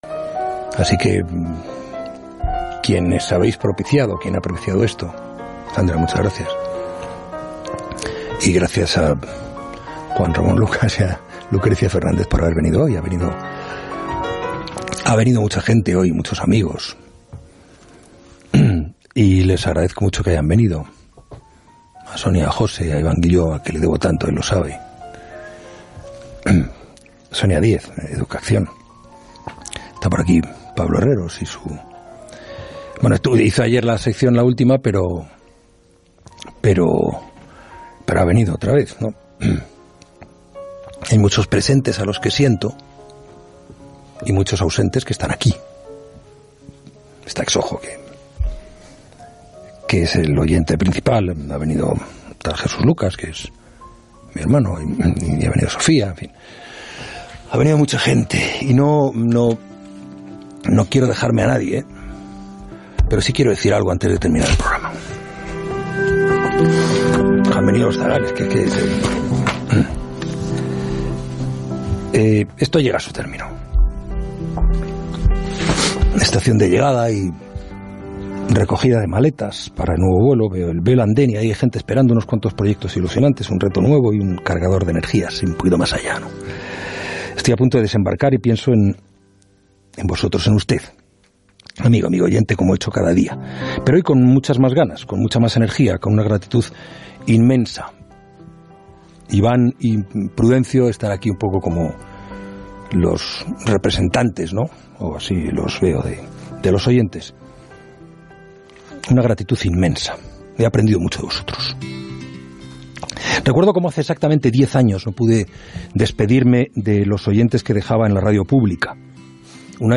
Agraïment a l'equip, comiat final i indicatiu del programa Gènere radiofònic Informatiu